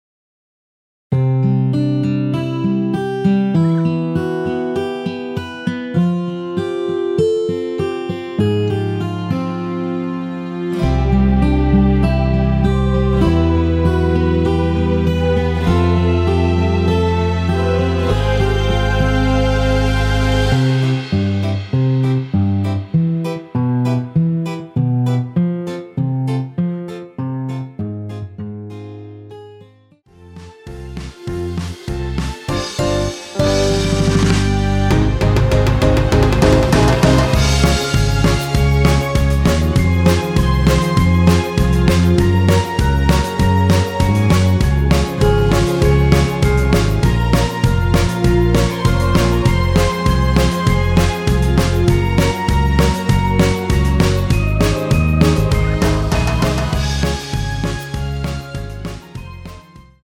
설레임과 달콤한 가사가 눈에 띄는 2013년 러브송
◈ 곡명 옆 (-1)은 반음 내림, (+1)은 반음 올림 입니다.
앞부분30초, 뒷부분30초씩 편집해서 올려 드리고 있습니다.
중간에 음이 끈어지고 다시 나오는 이유는